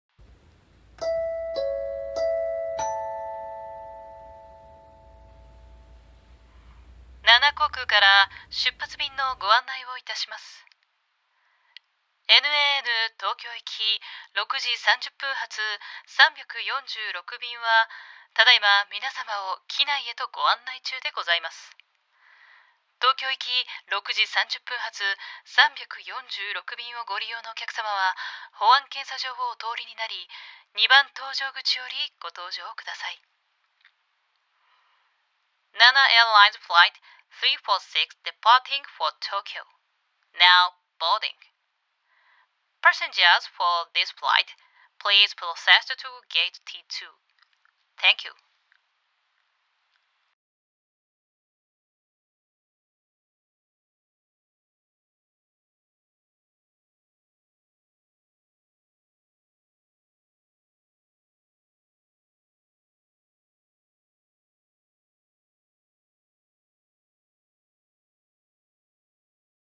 【声劇】空港アナウンス(ピンポンパンポン付き)